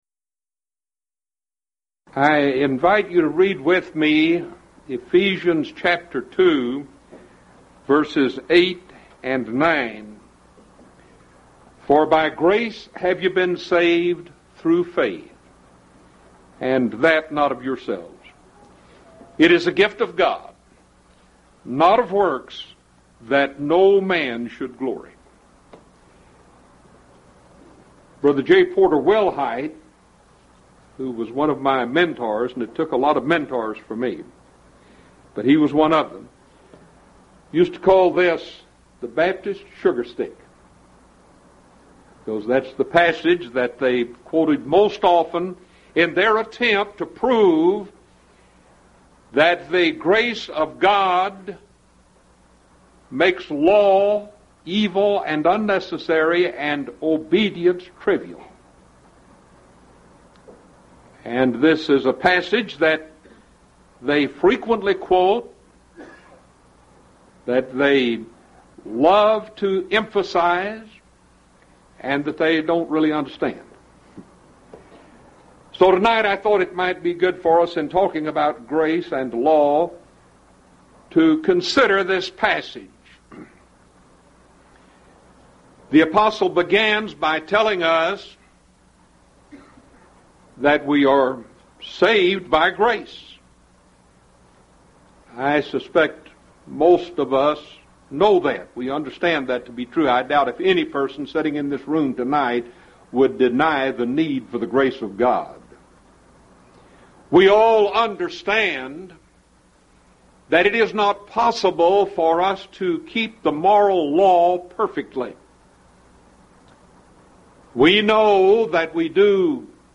Event: 1st Annual Lubbock Lectures
If you would like to order audio or video copies of this lecture, please contact our office and reference asset: 1998Lubbock20